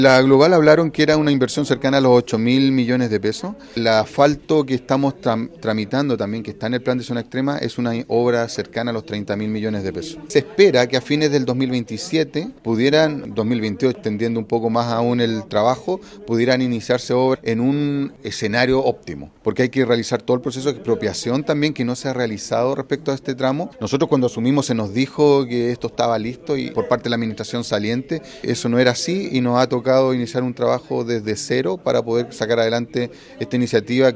Sobre los montos asociados a estas obras se refirió el alcalde Francisco Donoso.